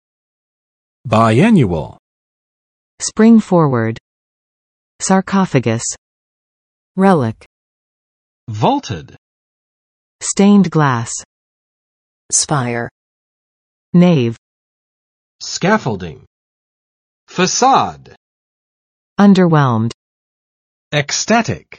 [baɪˋænjʊəl] adj. 一年两次的
biannual.mp3